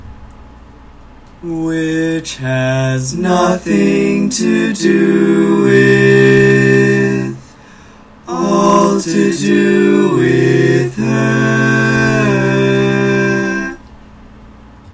Key written in: C Major
How many parts: 4
Type: Barbershop
All Parts mix: